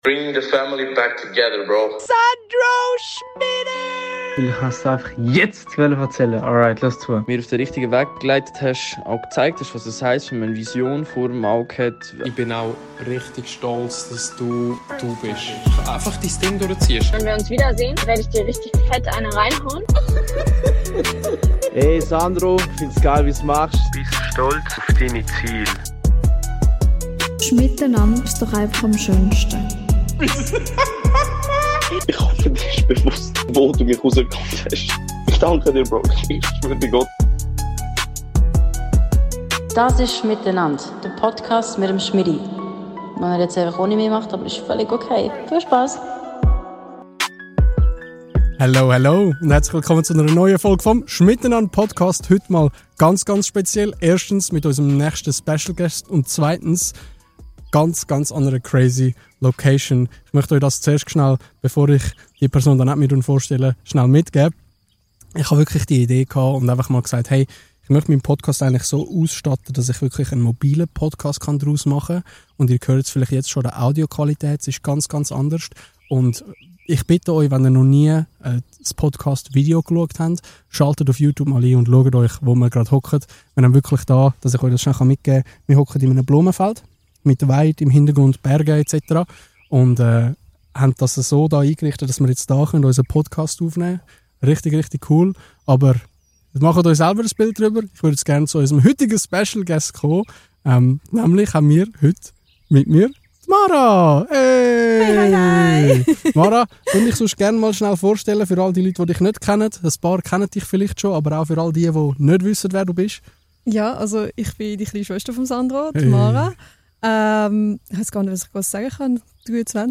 Lehn zrug, und gnüss de Vibe vo Natur & Deeptalk Love you Bro!